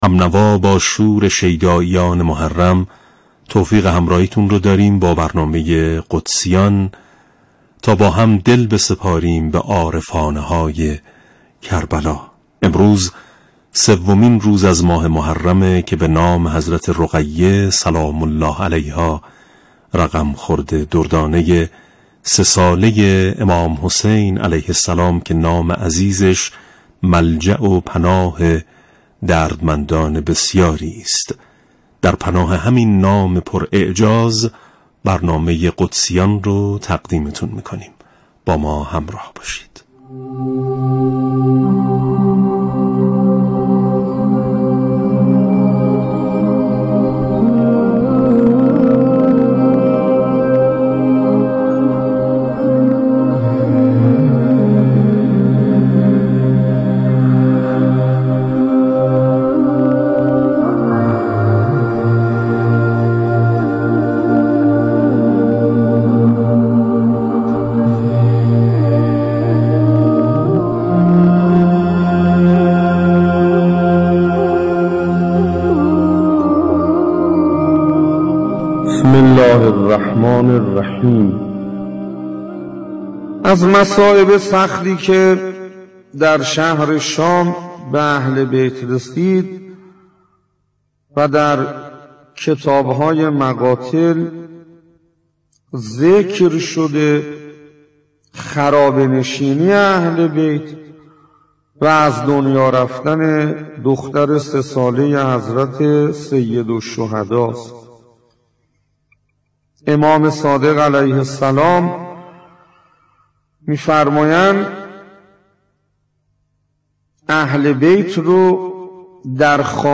به گزارش ایکنا، «قدسیان» عنوان ویژه‌برنامه شبکه رادیویی قرآن است که طی دهه نخست محرم‌الحرام به صورت زنده ساعت 10 پخش می‌شود.
این برنامه با هدف تبیین پیام‌های قرآنی واقعه کربلا و عاشورا با بخش‌هایی چون پخش قطعاتی از مداحی‌های به‌روز و متناسب با هر روز از دهه نخست محرم، نکاتی از سخنرانی خطبای شهیر در مصیبت واقعه کربلا، پخش خاطراتی از مخاطبان از سفر به عتبات عالیات، شعرخوانی توسط شعرای آیینی و نمایش‌نامه‌خوانی پخش می‌شود.